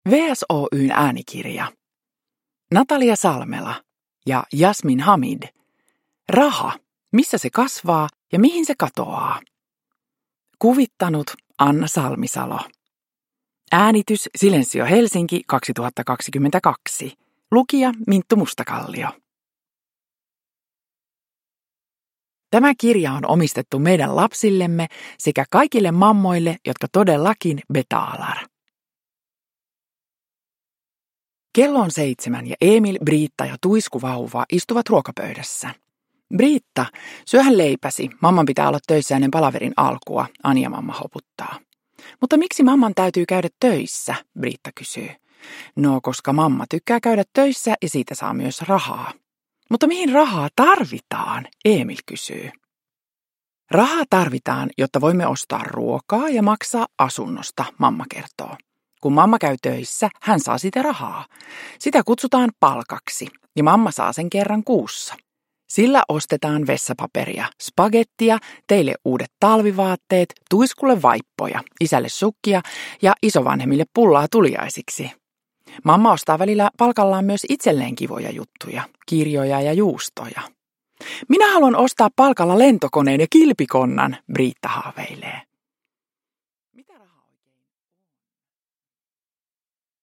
Uppläsare: Minttu Mustakallio